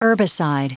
Prononciation du mot herbicide en anglais (fichier audio)
Prononciation du mot : herbicide